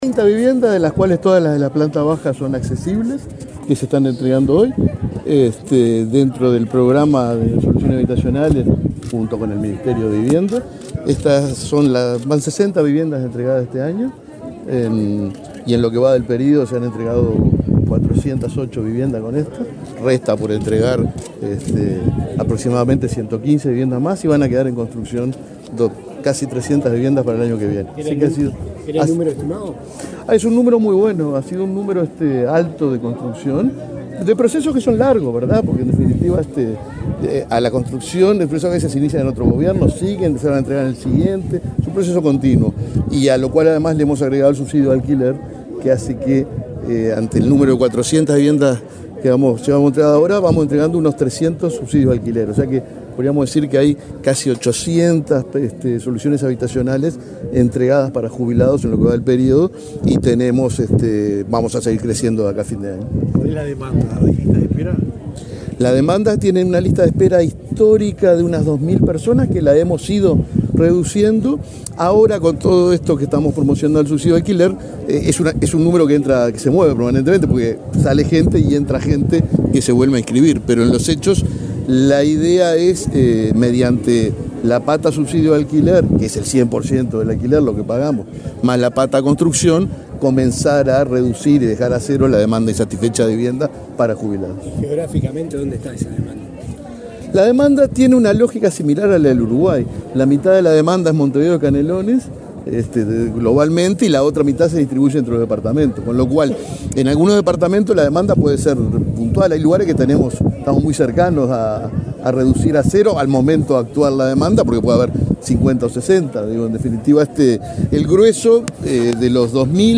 Declaraciones del presidente del BPS, Alfredo Cabrera
El presidente del Banco de Previsión Social (BPS), Alfredo Cabrera, dialogó con la prensa, luego de participar en la inauguración de viviendas para